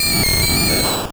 Cri de Fantominus dans Pokémon Or et Argent.